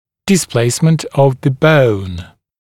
[dɪs’pleɪsmənt əv ðə bəun][дис’плэйсмэнт ов зэ боун]перемещение кости